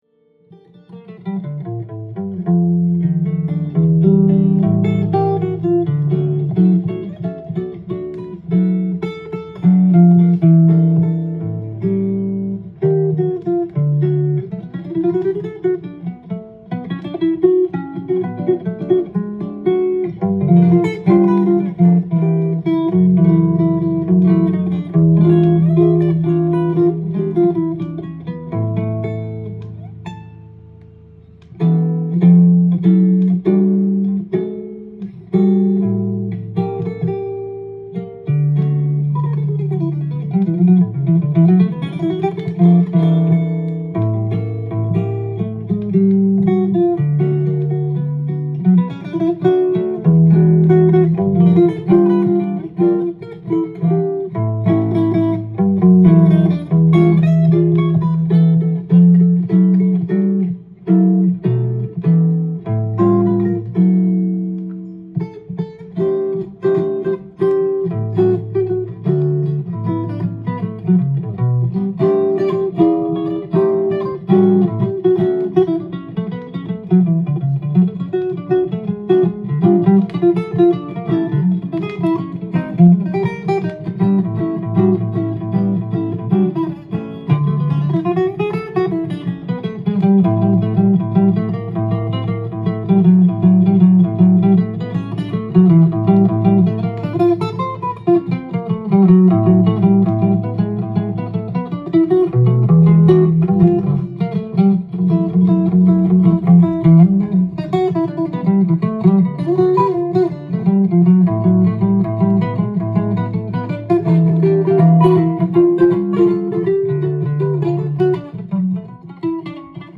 店頭で録音した音源の為、多少の外部音や音質の悪さはございますが、サンプルとしてご視聴ください。
素晴らしいの一言の、絶品ジャズギター！！！アンプを通さず生音をマイクで拾って録音したことでも有名（A3のみアンプ録音）。